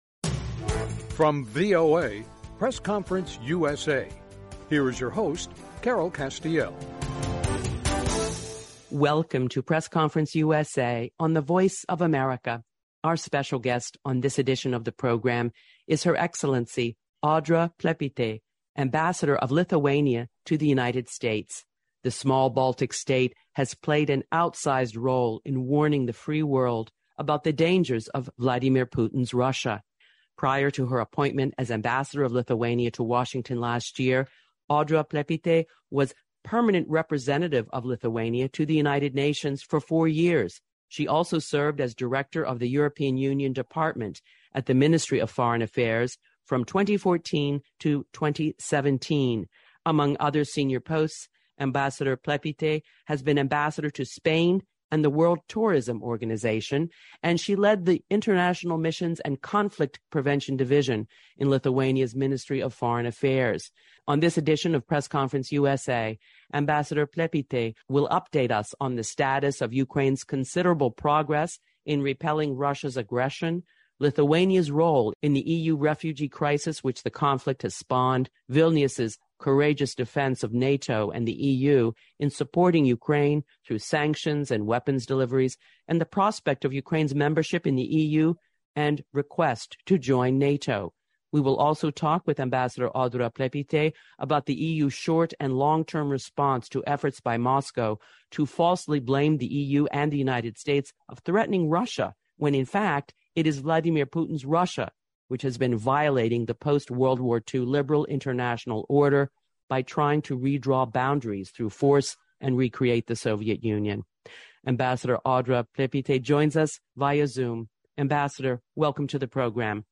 A Conversation with H.E. Audra Plepytė, Lithuanian Ambassador to the US